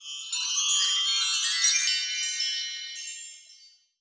magic_sparkle_chimes_01.wav